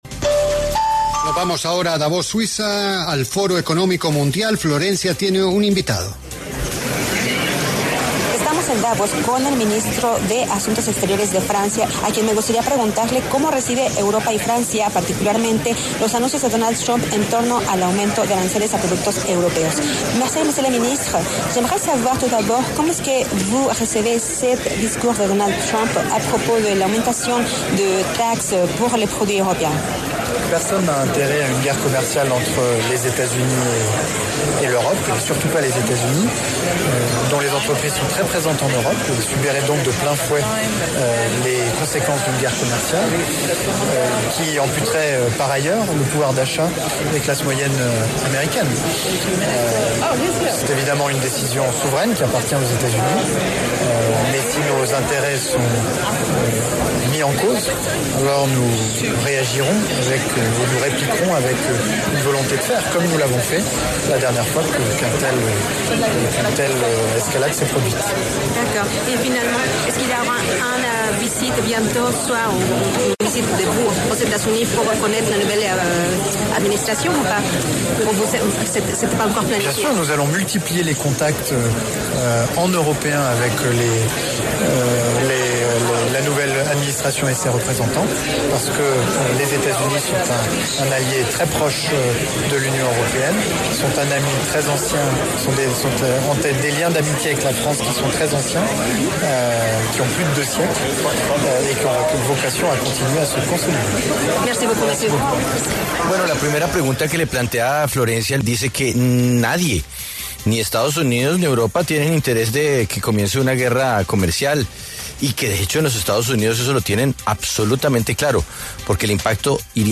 El ministro de Asuntos Exteriores de Francia, Jean-Noël Barrot, pasó por los micrófonos de La W en el marco de la realización del Foro de Davos y afirmó que ni Estados Unidos ni Europa tienen interés de iniciar una guerra comercial.